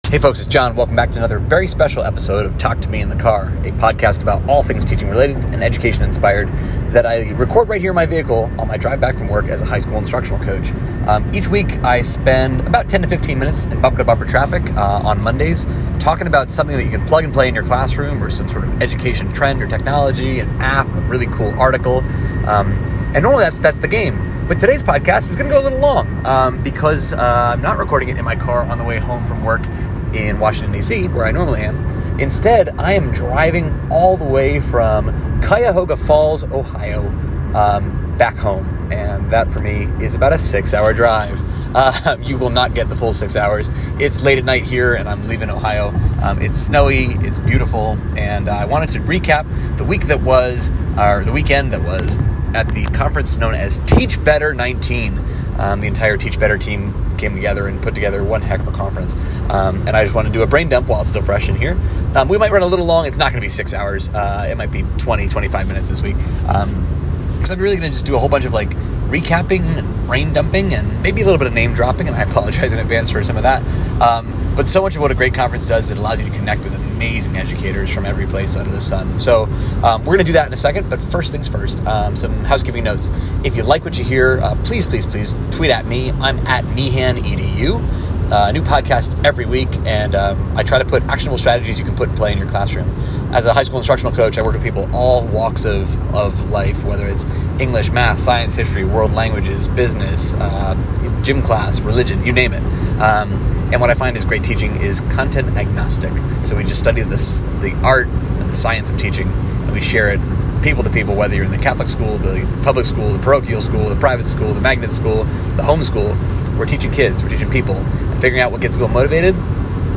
Recorded in bumper-to-bumper traffic